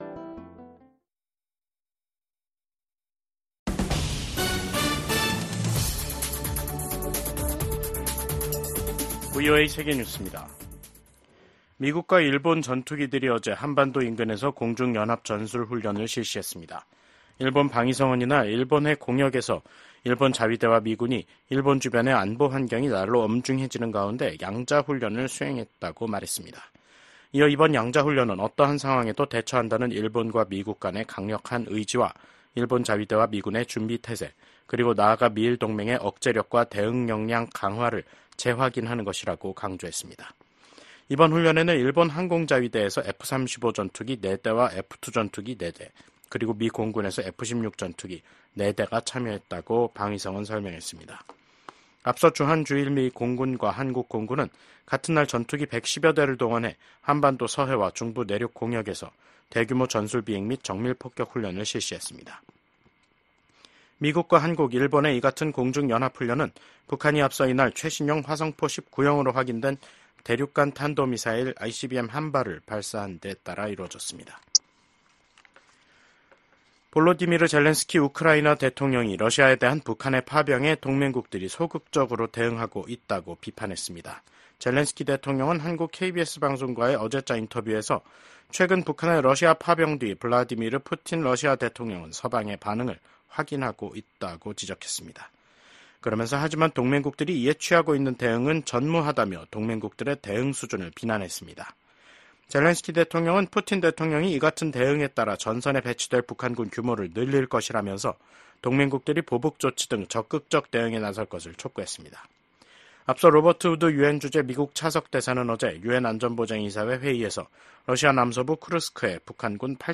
세계 뉴스와 함께 미국의 모든 것을 소개하는 '생방송 여기는 워싱턴입니다', 2024년 11월 1일 저녁 방송입니다. 미국 대선에서 선거일 전에 투표하는 사람의 비율이 50%를 넘을 것으로 전망된다고 여론조사 업체인 갤럽이 발표했습니다. 미국 정부 고위 관리들이 베냐민 네타냐후 이스라엘 총리를 만나 헤즈볼라와의 휴전 문제를 논의했습니다.